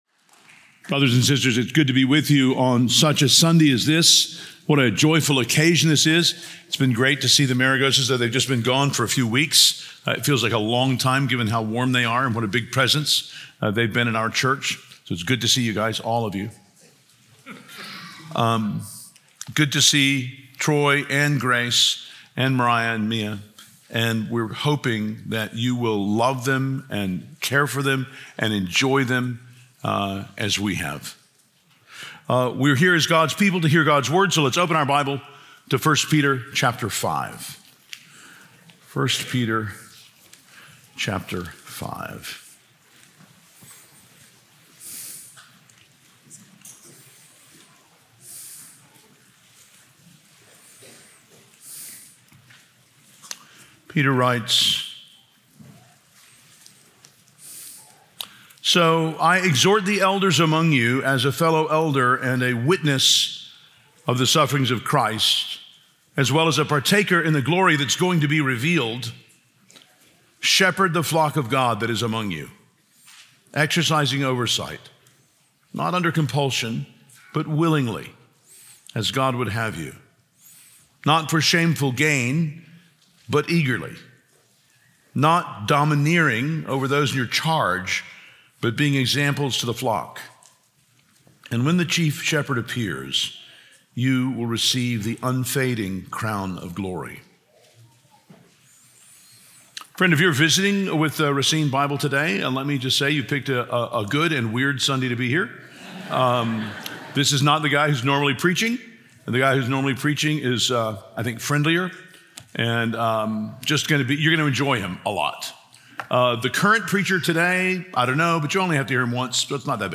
Four Priorities of a Pastor Date August 24, 2025 Speaker Mark Dever Topic All Sermons Book 1 Peter Watch Listen Save 1 Peter 5:1-4 Audio (MP3) Previous The Path of God's Grace Next The Meaning of God’s Grace